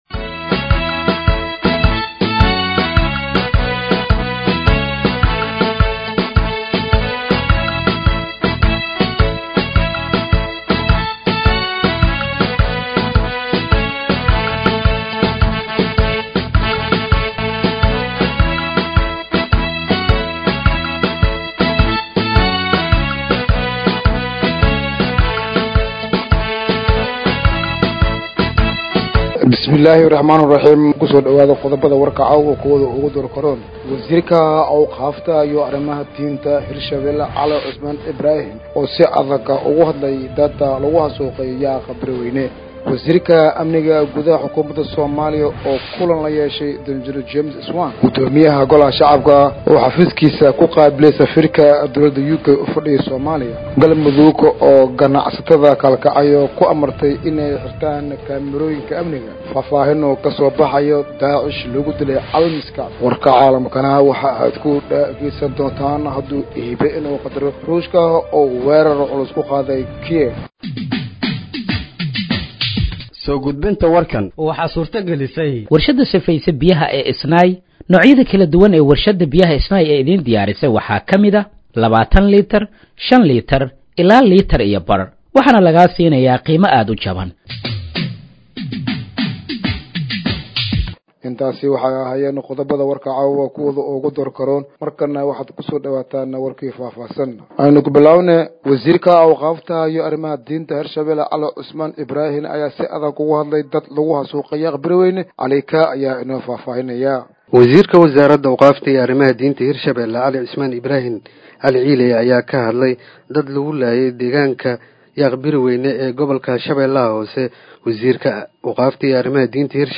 Dhageeyso Warka Habeenimo ee Radiojowhar 28/09/2025